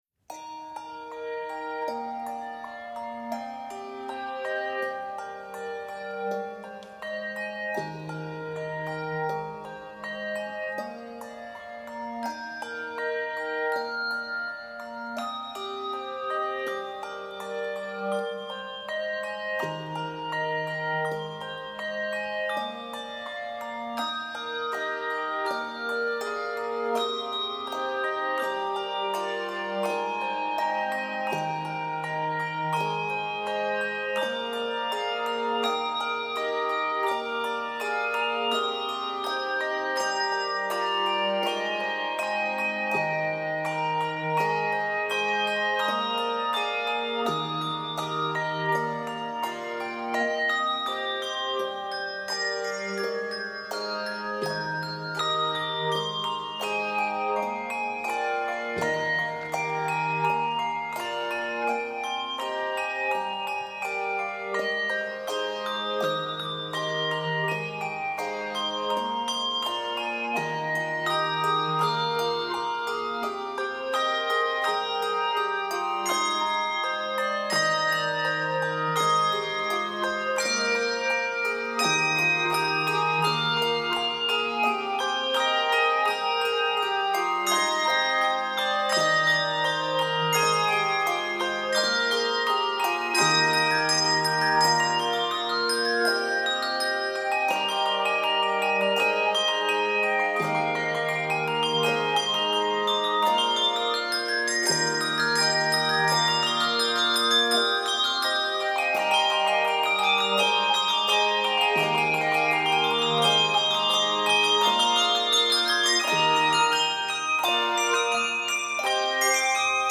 Key of Eb Major.